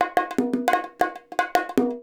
100 BONGO7.wav